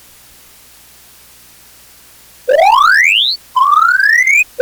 A continuación añadimos un tercer experimento sencillo que recoge las características explicadas en los dos casos anteriores